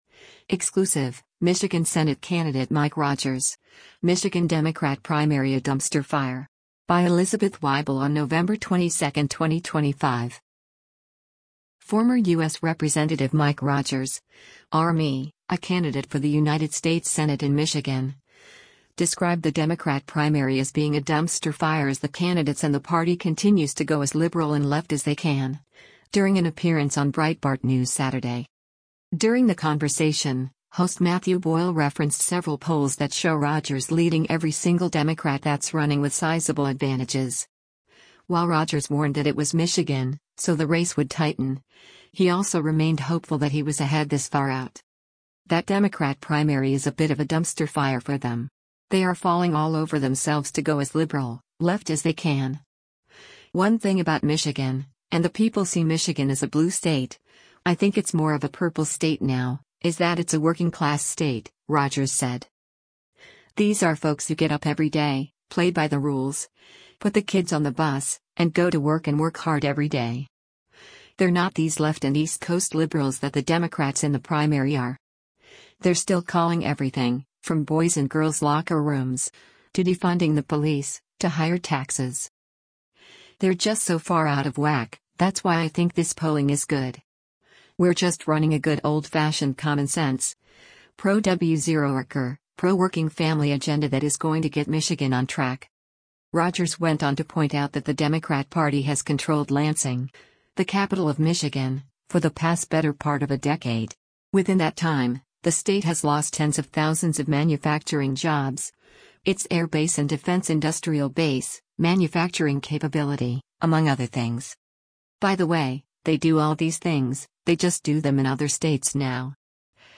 Former U.S. Rep. Mike Rogers (R-MI), a candidate for the United States Senate in Michigan, described the Democrat primary as being a “dumpster fire” as the candidates and the party continues to “go as liberal” and left as they can, during an appearance on Breitbart News Saturday.